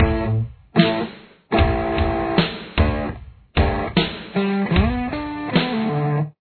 It’s at about 75 bpm: